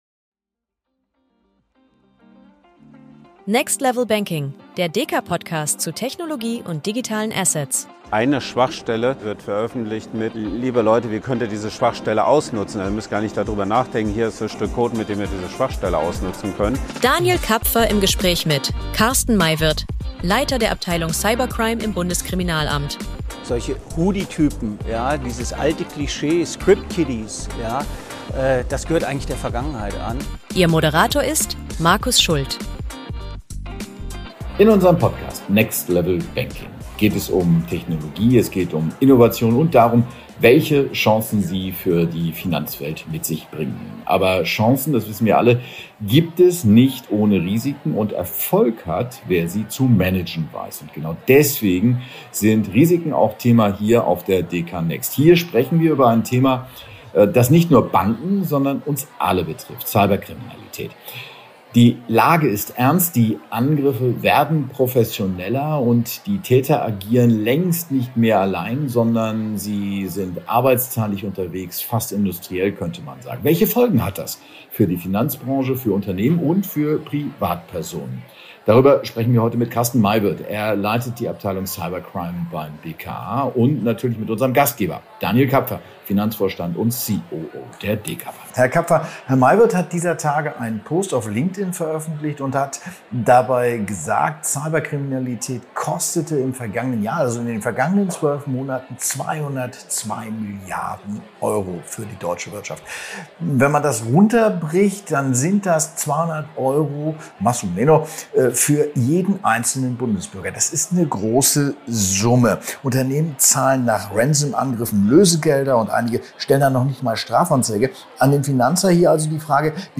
Auch in dieser Episode sind wir zu Gast auf der DekaNXT, der Zukunftskonferenz der Deka.